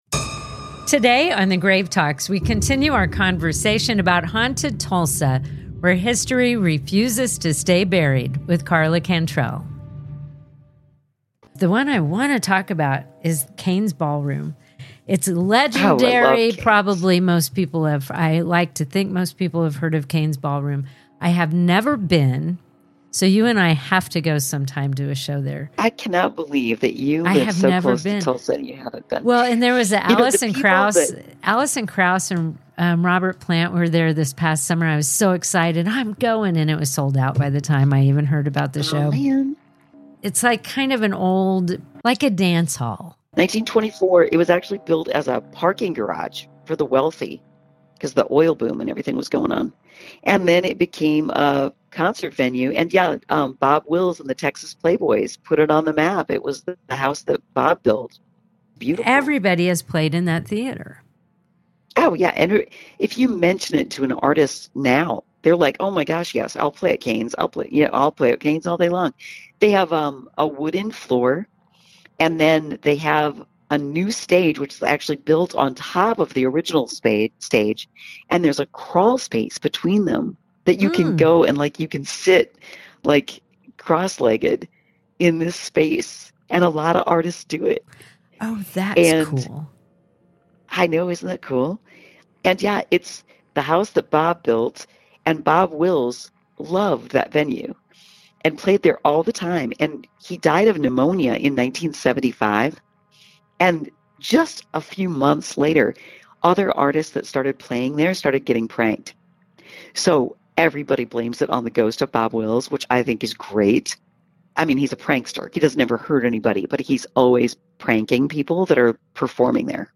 Together, we explore the tragedies that shaped the city and the hauntings that linger long after the headlines ended. This is Part Two of our conversation.